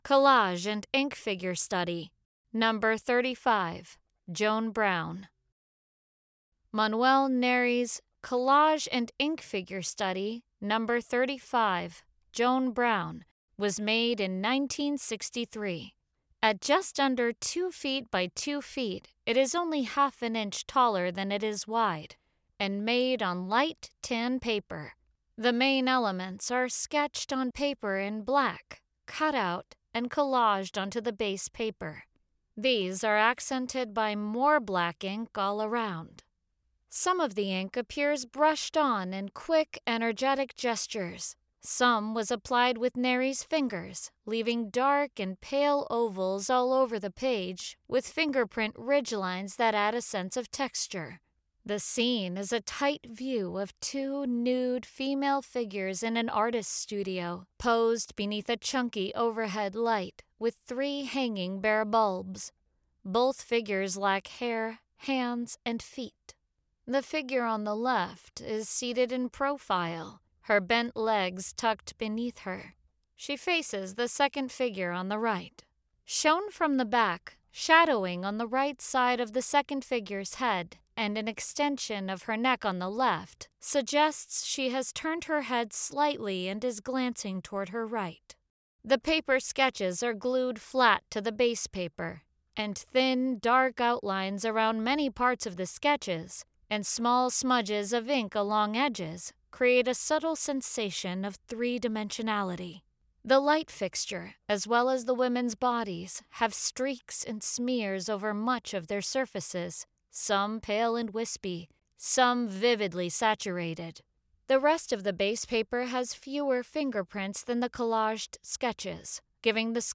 Audio Description (03:19)